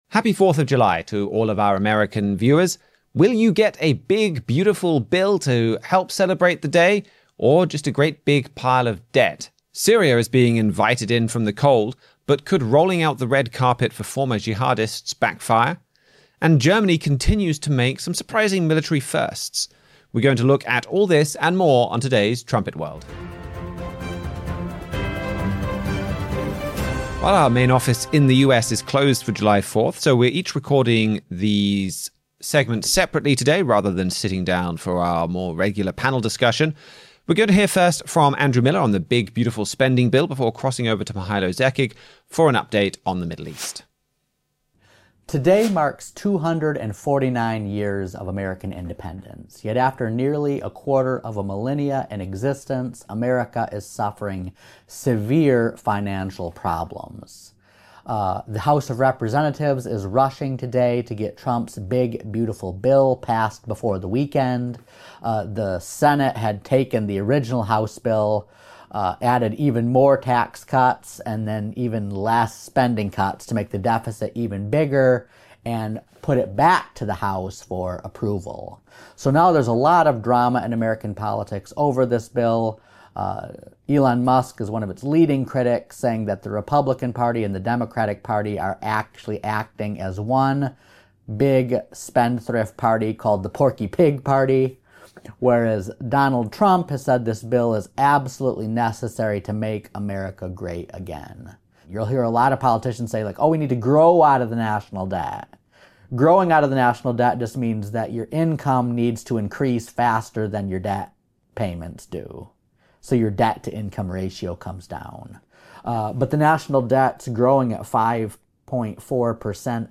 On this 4th of July episode, we let our American correspondents have the day off so we pre-recorded segments for today’s week in review.